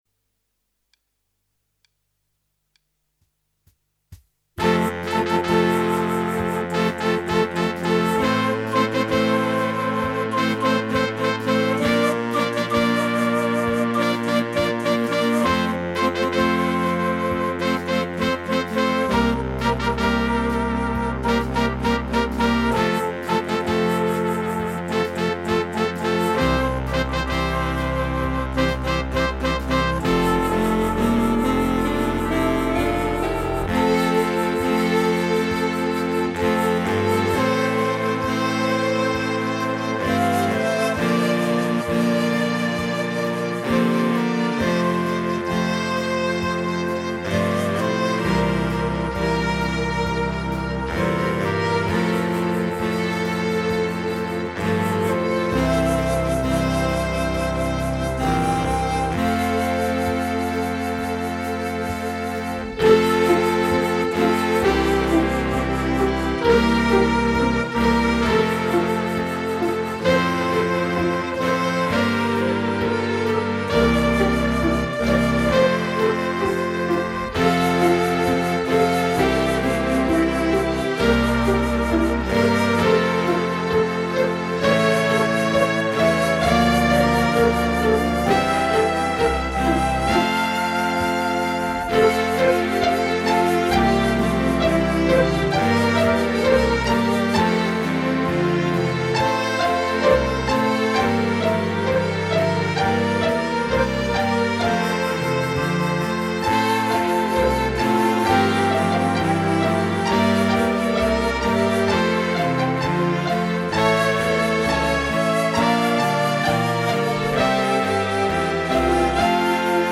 Prelude orchestre.mp3